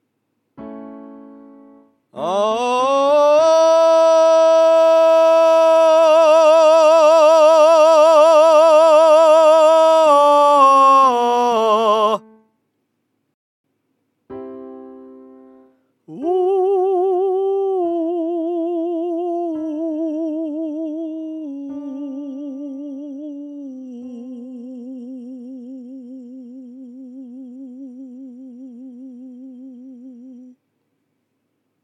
声区融合（ミックスボイス）できる基準をクリアしている地声・裏声の参考音源
音量注意！
ここまでに解説した各要素をあれこれ取り入れた状態の地声と裏声を発声してみました。
この参考音源では喉頭の位置は極端に上がり下がりしていない中庸な音色ですが、これも前述の通り暗くしたり明るくして変えてみたり、音量もより大きく／小さく出したり、コブシ的な大きな動きを付けたりストレートで発声してみたり、母音を変えてみたり子音を付けてみたりと、組み合わせは山程ありますが、ある程度どういった状態でも対応できる喉の状態になっていればOKです。
standards-achieving-mixedvoice-01.mp3